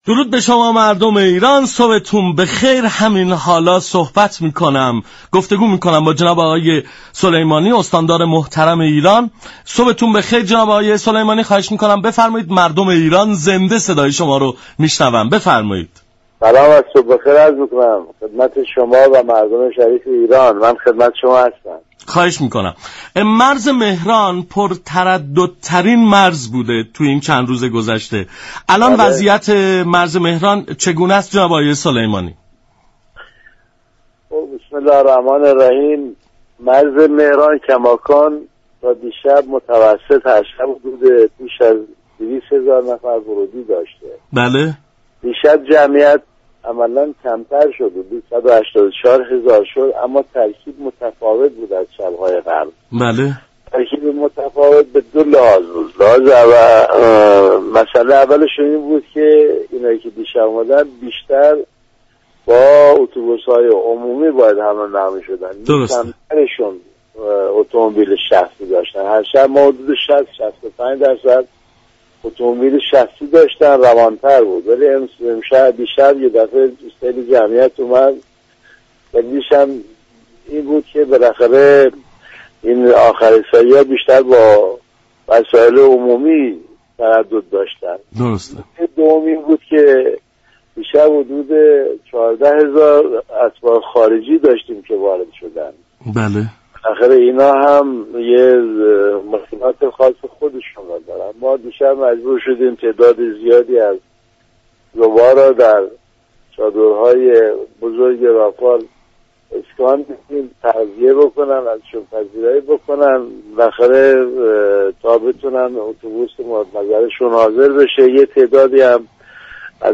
سلیمانی استاندار ایلام در گفت و گو با رادیو ایران گفت: 184 هزار نفری كه شب گذشته ( بیست و هشتم مهر) به مرز مهران وارد شدند از اتوبوس های عمومی استفاده كرده بودند.